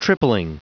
Prononciation du mot tripling en anglais (fichier audio)
Prononciation du mot : tripling